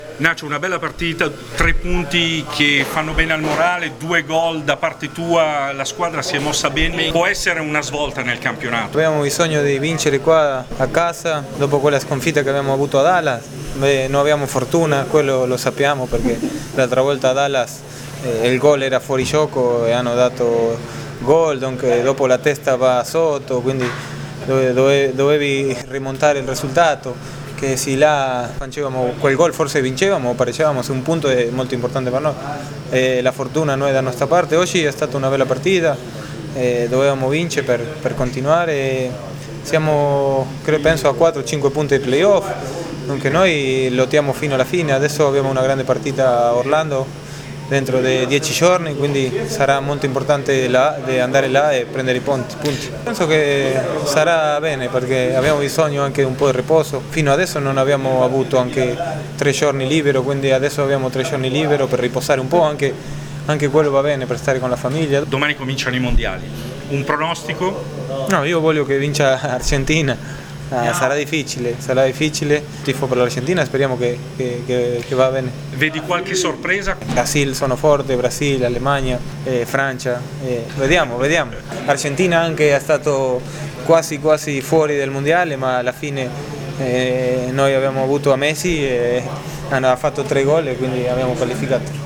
Interviste post partita: